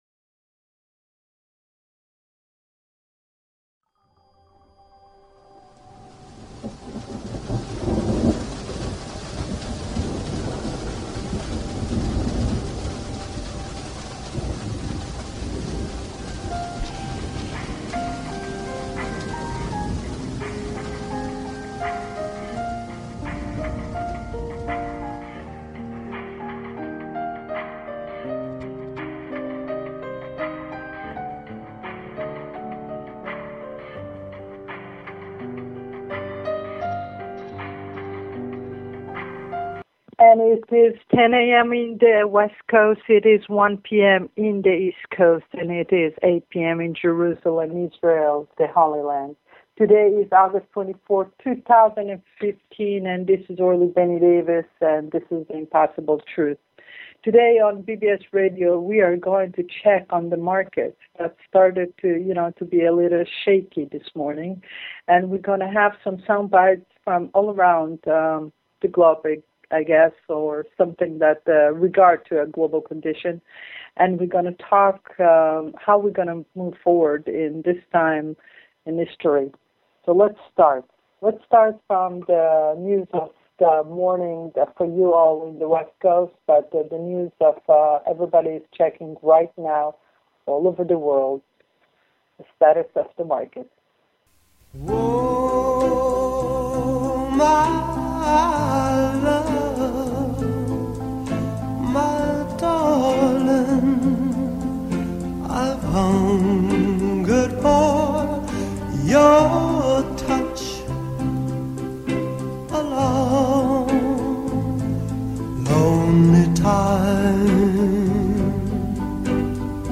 The Impossible Truth on BBS Radio.